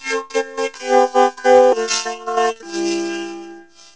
Here are some examples I made using my PAiA vocoder, a graphic EQ on the
microphone, Jupiter 6, and Korg T1 keyboards: These are also available as more
sing2.wav